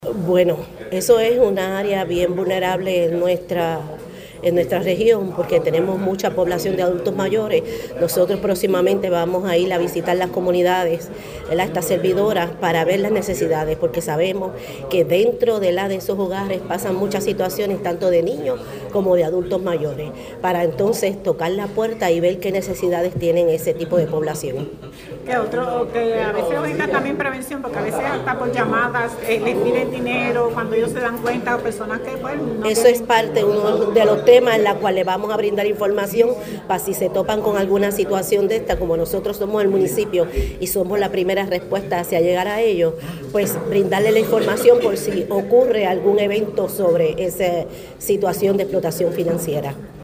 La alcaldesa confirmó además que se estará dando orientación a las personas de la tercera edad en su municipio para evitar que sean víctimas de fraude financiero.